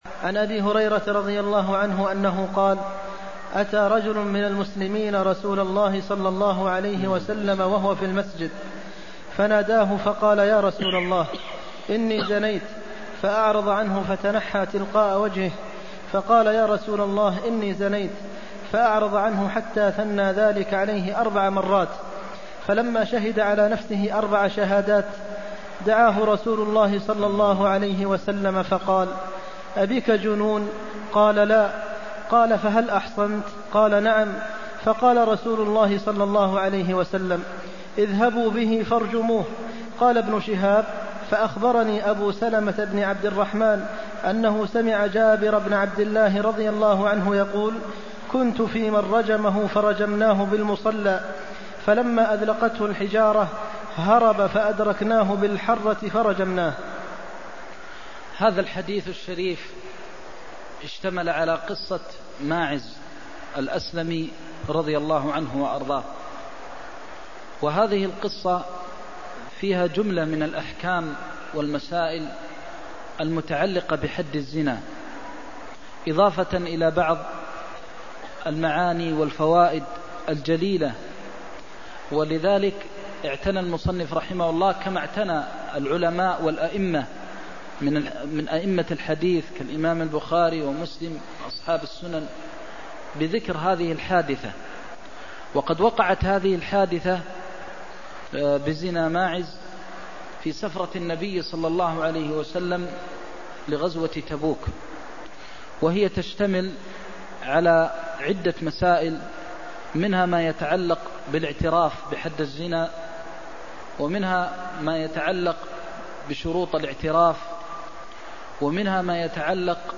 المكان: المسجد النبوي الشيخ: فضيلة الشيخ د. محمد بن محمد المختار فضيلة الشيخ د. محمد بن محمد المختار هديه فيمن اعترف على نفسه بالزنا (331)